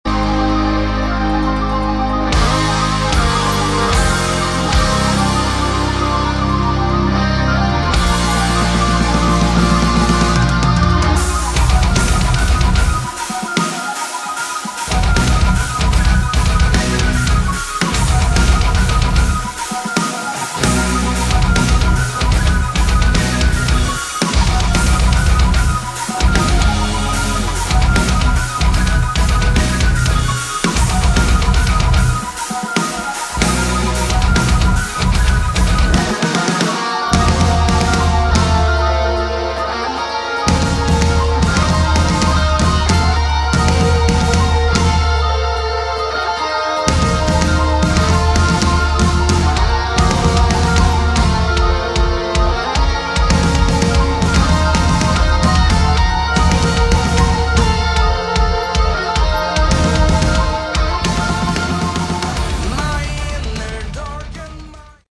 Category: Progressive Metal